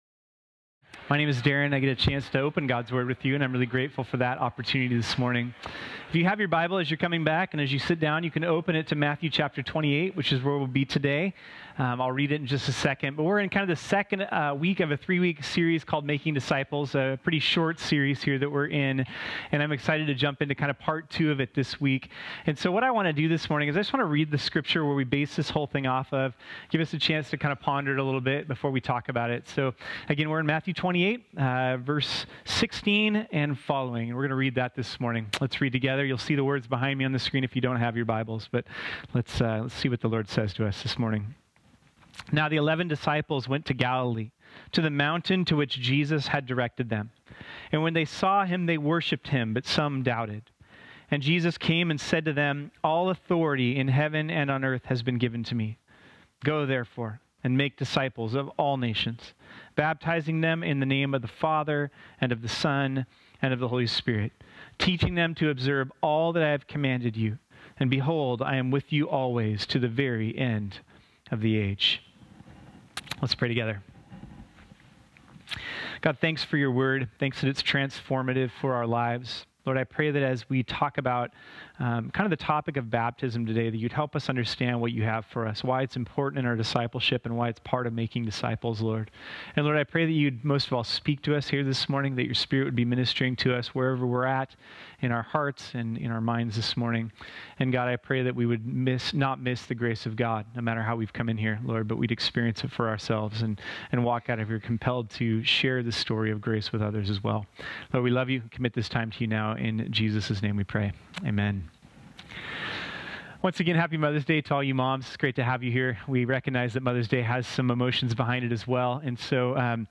This sermon was originally preached on Sunday, May 13, 2018.